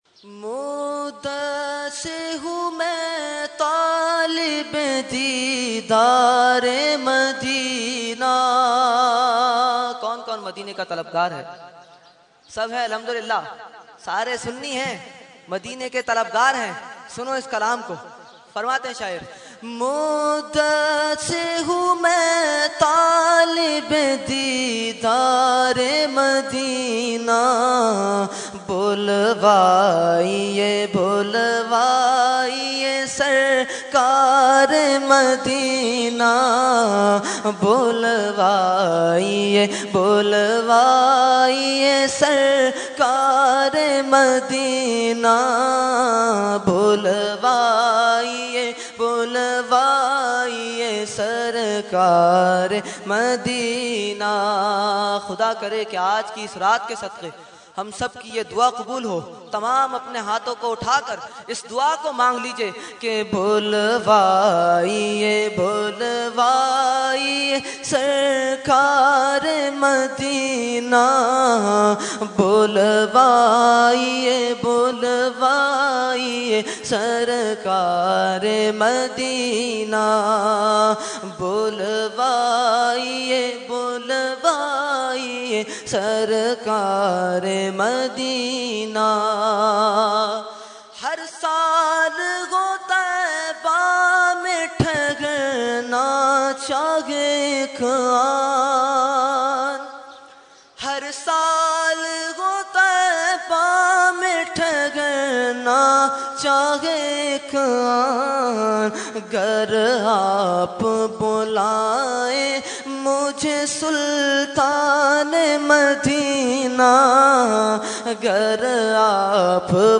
Category : Naat | Language : UrduEvent : Shab e Baraat 2015